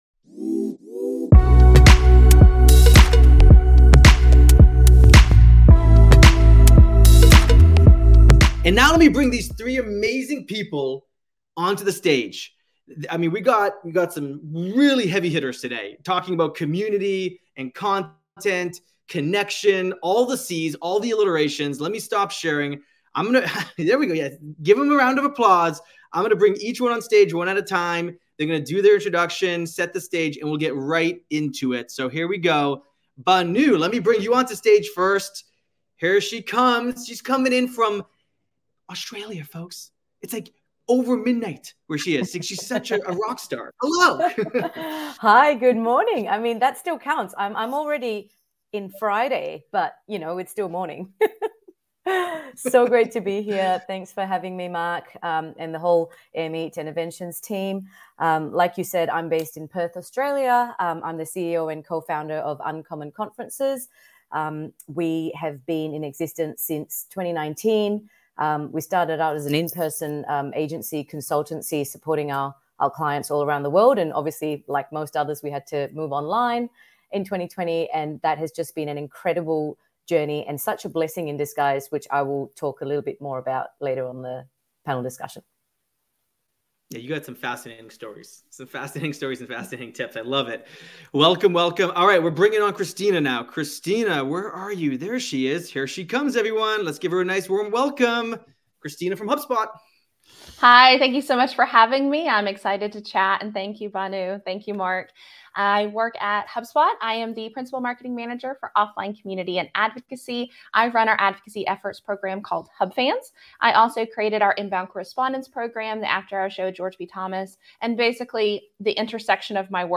During our recent live Eventions episode: From Content-first to Connection-first Events , they dove deep into how events and community go hand in hand and why you need to put connections first.